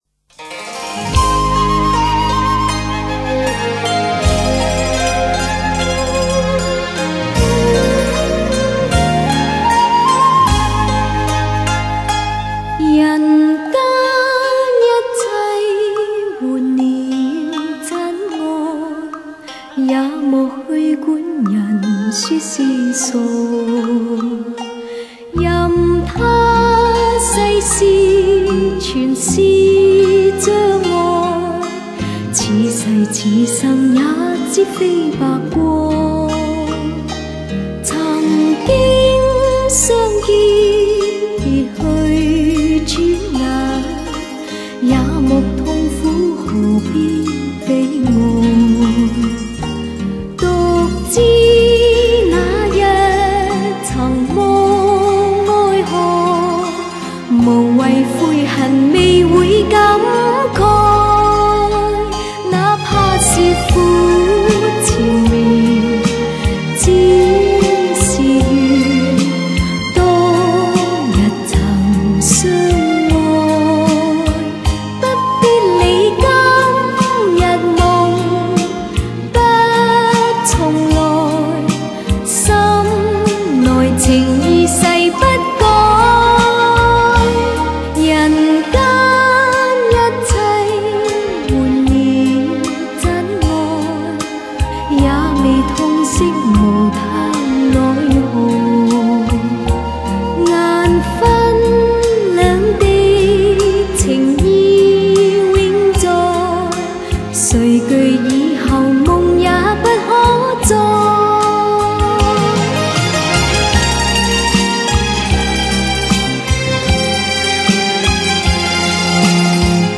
她的歌声温柔甜美，婉转悦耳，清新雅致，犹如一阵阵春风吹